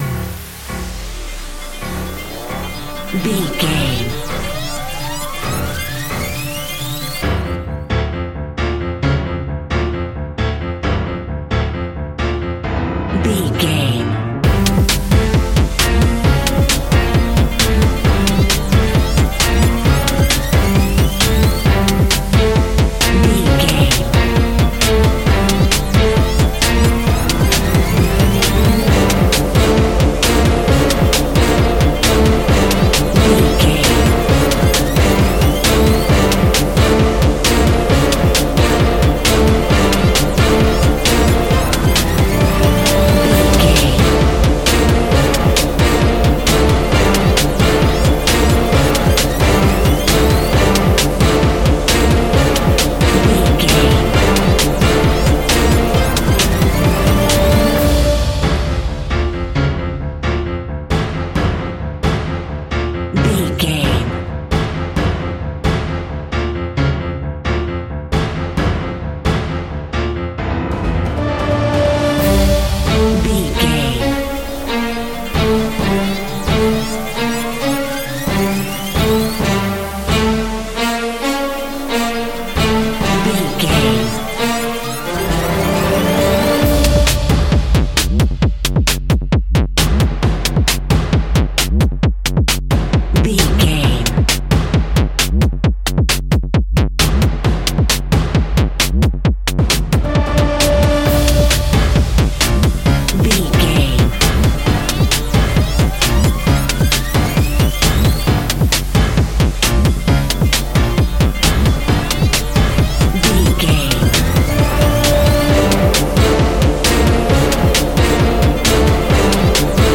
In-crescendo
Thriller
Aeolian/Minor
D
scary
tension
ominous
dark
suspense
haunting
eerie
piano
drum machine
synthesizer
pads